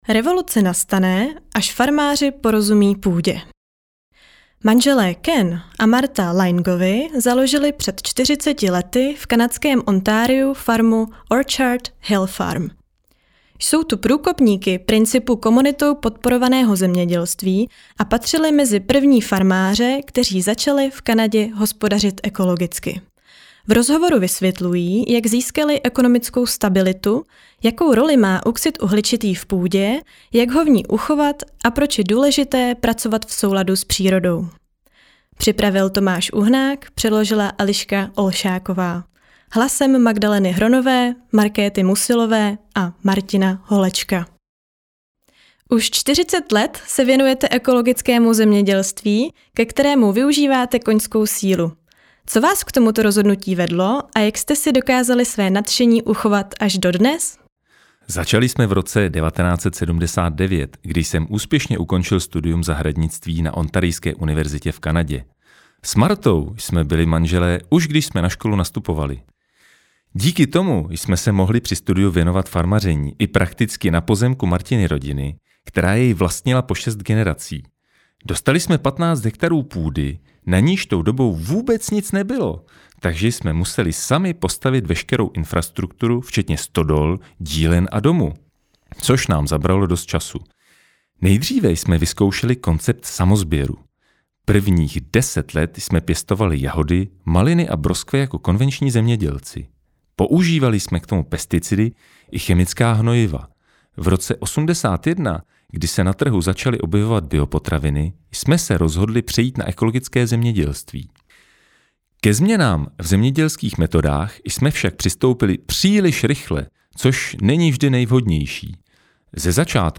V rozhovoru vysvětlují, jak získali ekonomickou stabilitu, jakou roli má oxid uhličitý v půdě, jak ho v ní uchovat a proč je důležité pracovat v souladu s přírodou.